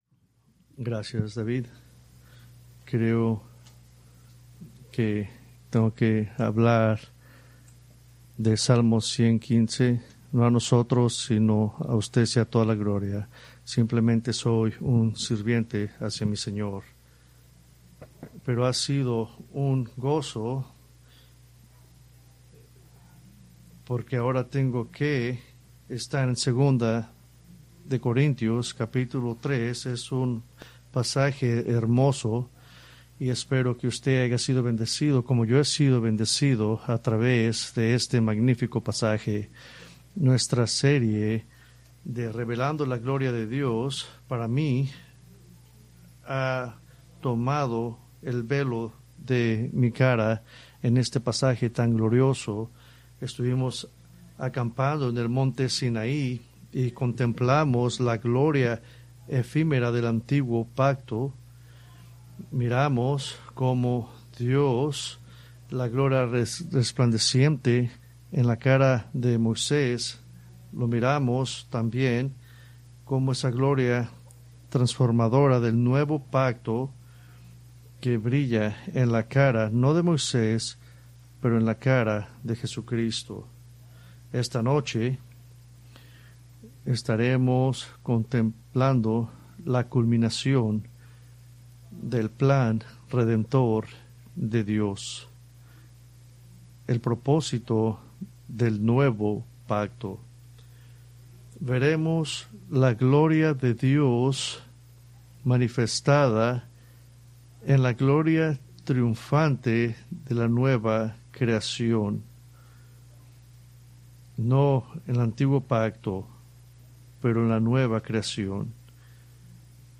Preached June 1, 2025 from 2 Corintios 4:1-6